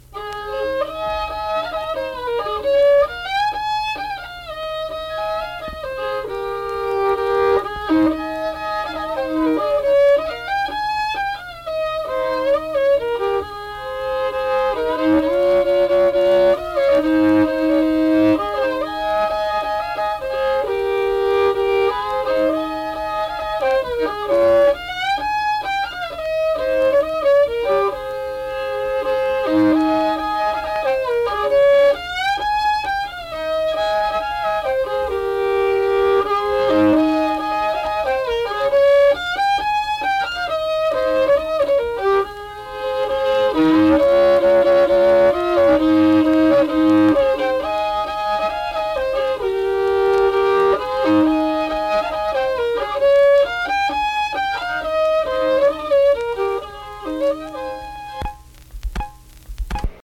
Unaccompanied fiddle music and accompanied (guitar) vocal music
Verse-refrain 2(1).
Instrumental Music
Fiddle